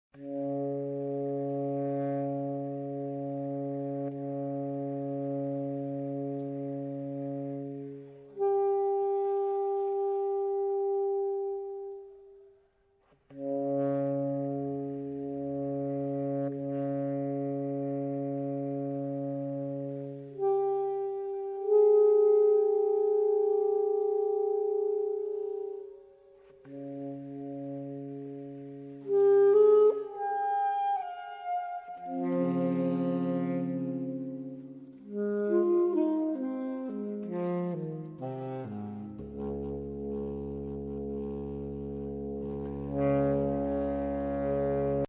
Obsazení: 4 Saxophone (AATBar)